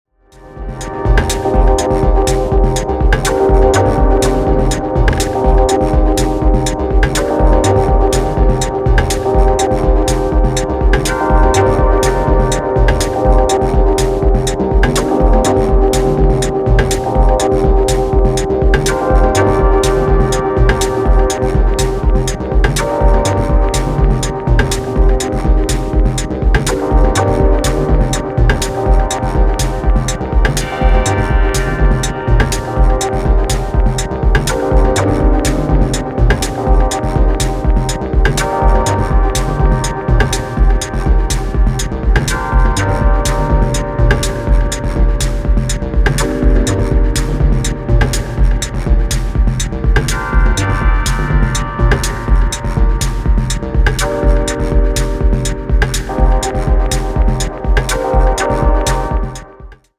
Electronix Techno